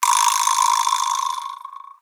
vibraslap-large02.wav